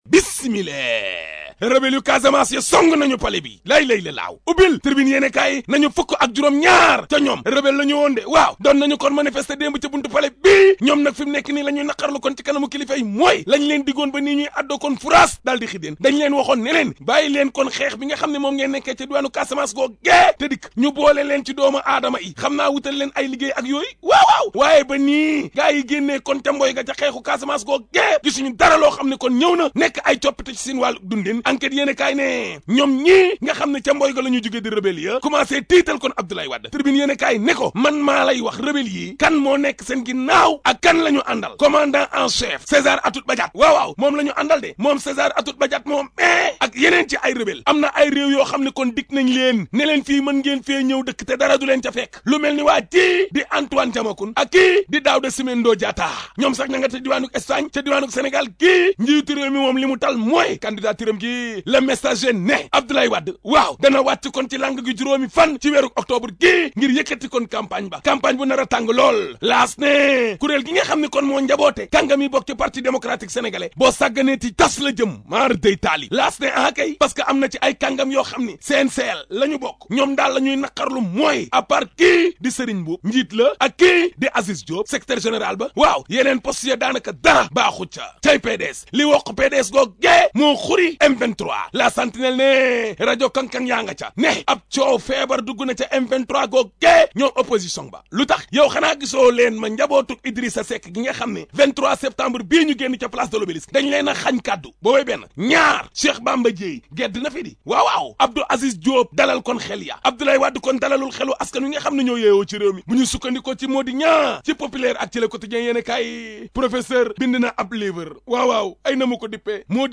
[ AUDIO ] La revue de presse d'Ahmed Aidara du 30 Septembre ( ZIK FM )